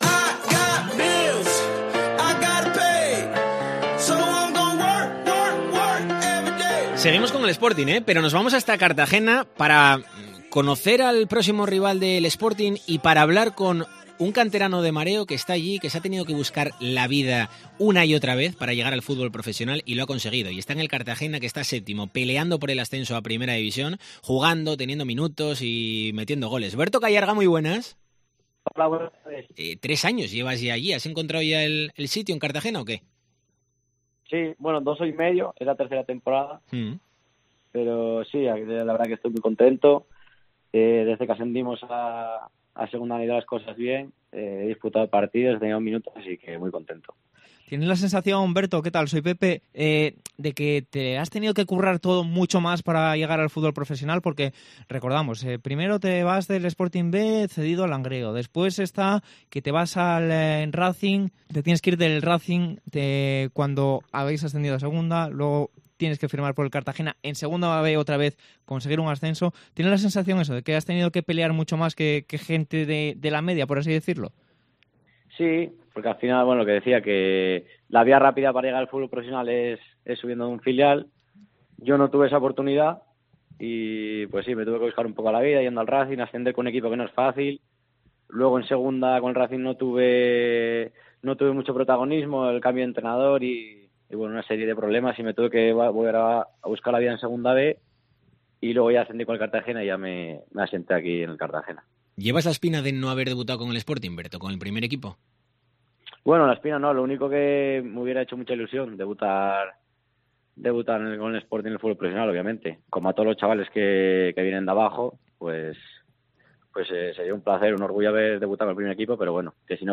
Entrevista a Berto Cayarga en DCA